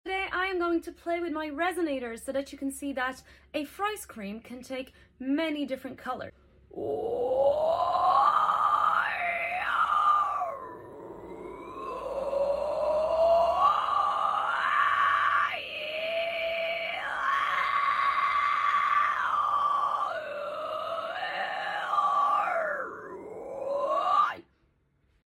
Fry scream resonance is key sound effects free download
Fry scream resonance is key for the texture! Do this with different vowels to find your favorite 🤩